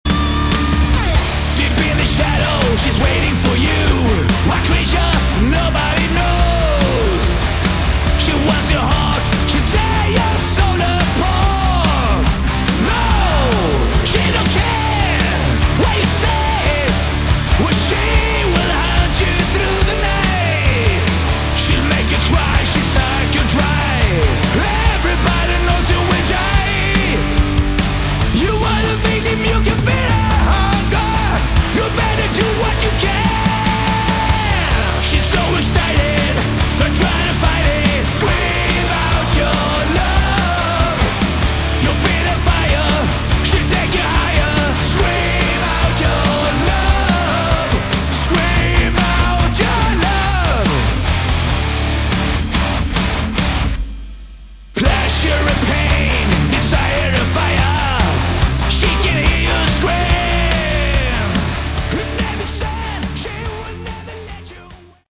Excellent sleazy hard rock!
This is good old-school dirty sleazy hard rock.